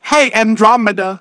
hey_andromeda/ovos-tts-plugin-deepponies_Discord_en.wav · OpenVoiceOS/synthetic-wakewords at main
ovos-tts-plugin-deepponies_Discord_en.wav